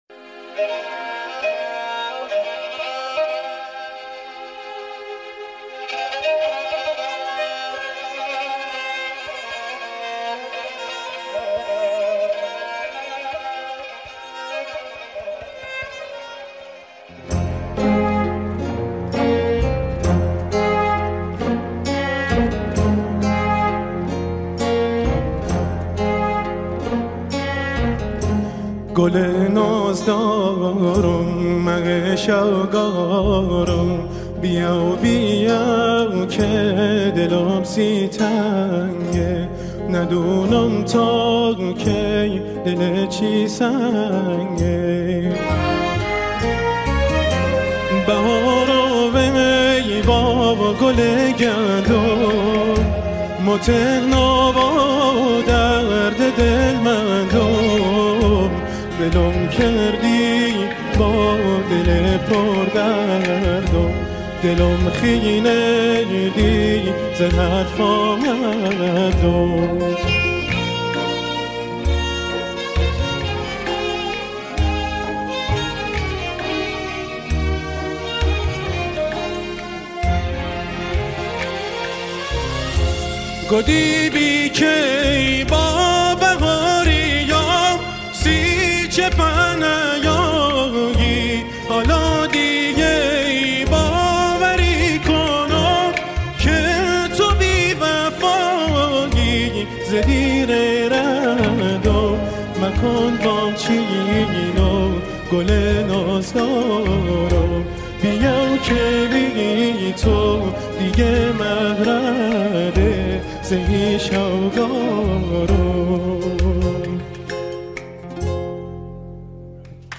ترانه لری بختیاری